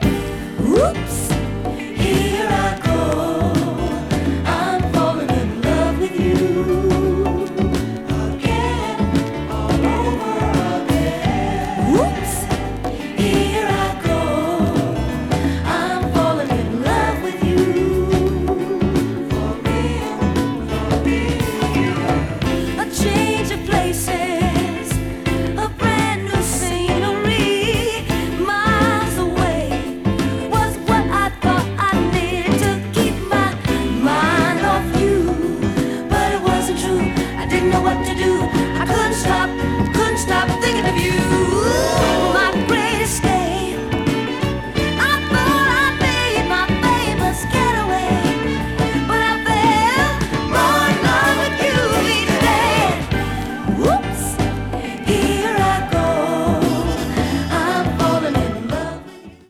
70s MELLOW SOUL / FUNK / DISCO 詳細を表示する